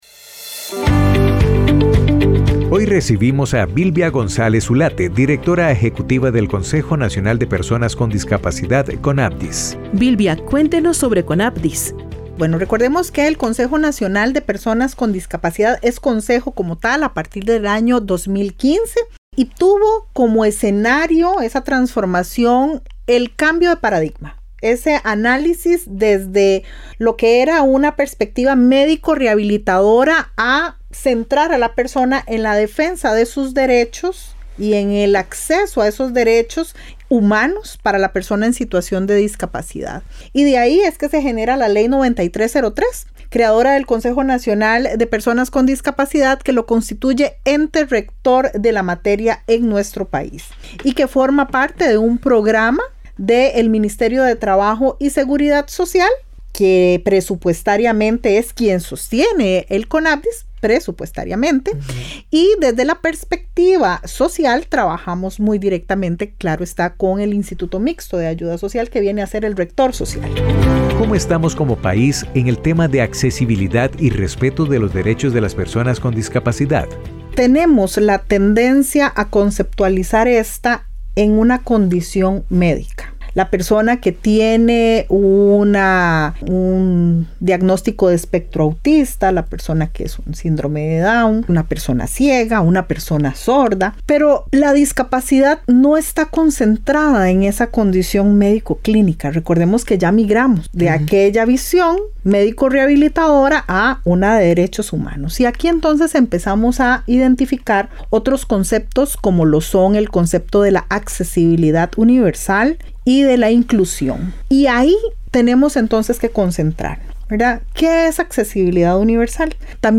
Conversamos con Bilbia González, directora ejecutiva del Conapdis